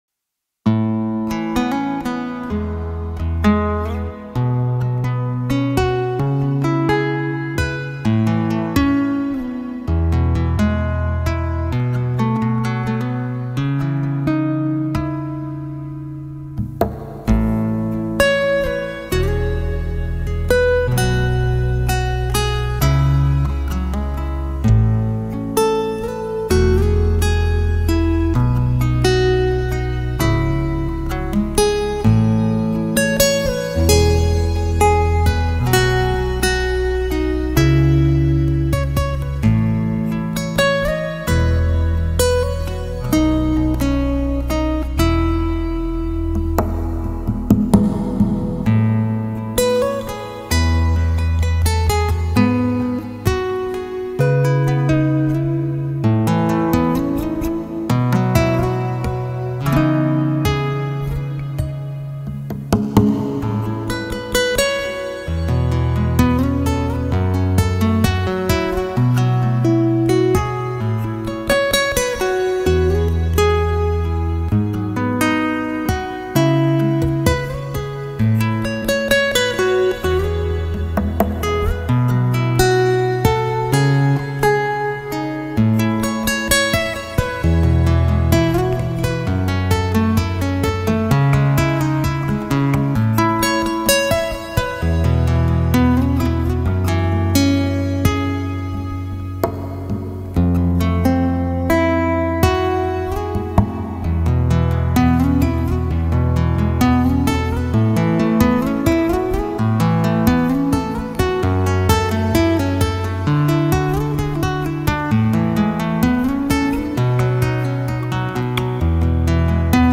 Best-Relax-MusicBeautiful-Relaxing-MusicRelaxing-Guitar-Music-Instrumental-MusicCalming-Music.mp3